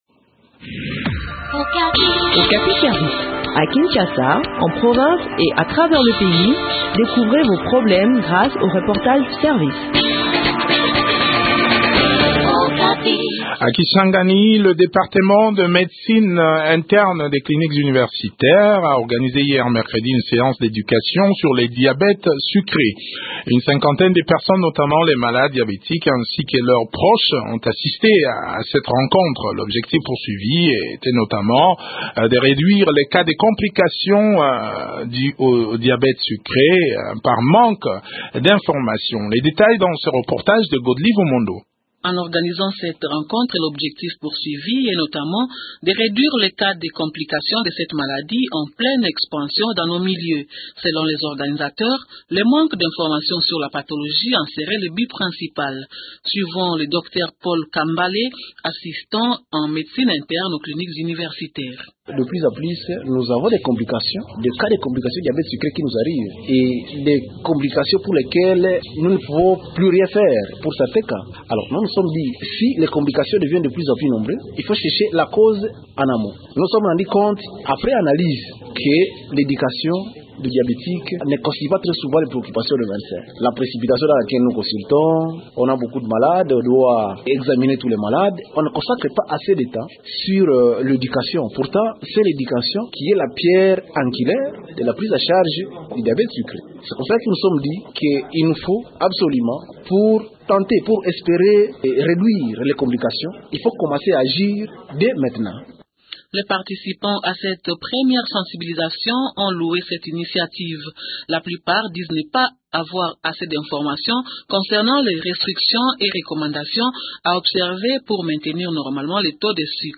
est interviewé